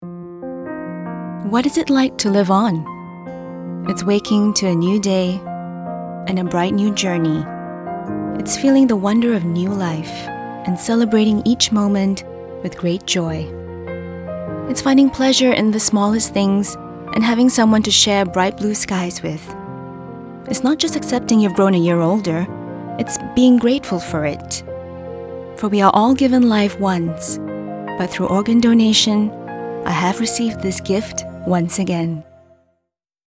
Inglês (Singapura)
~ Uma dubladora calorosa, amigável e versátil com apelo global ~ Baseada em Austin
Conversacional
Amigáveis
Natural